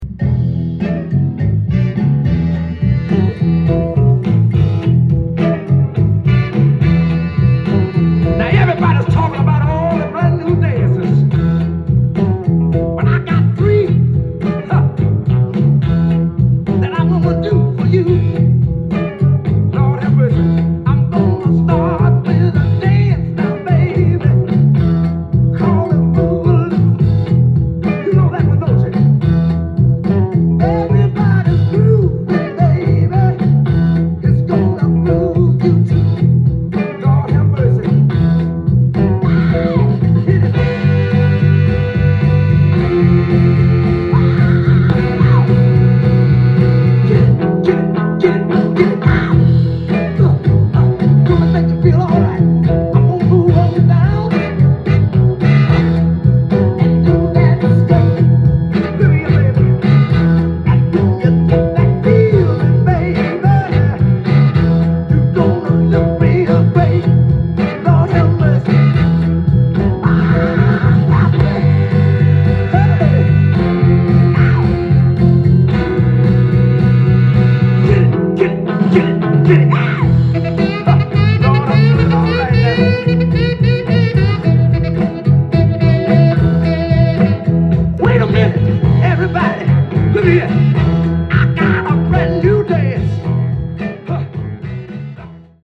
ジャンル：FUNK
店頭で録音した音源の為、多少の外部音や音質の悪さはございますが、サンプルとしてご視聴ください。
ファンキーでディープなヘヴィ級ソウルの連続にノックアウト！